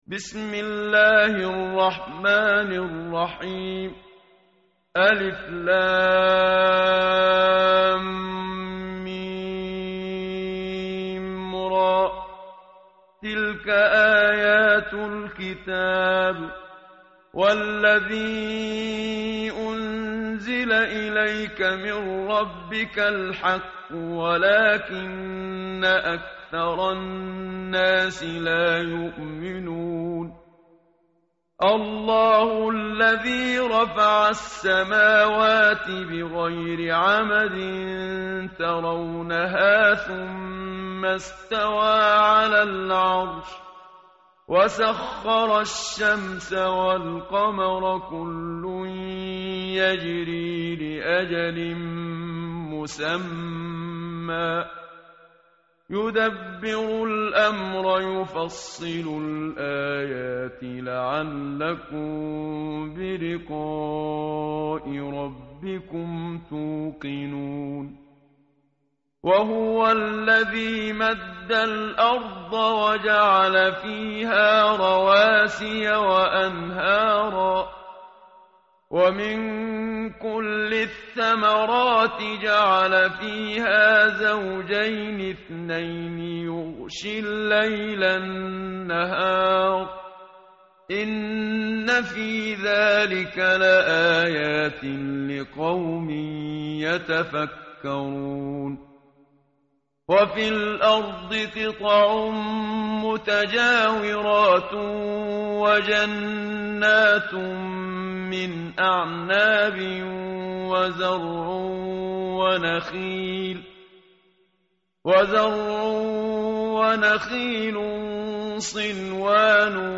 tartil_menshavi_page_249.mp3